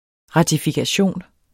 Udtale [ ʁɑdifigaˈɕoˀn ]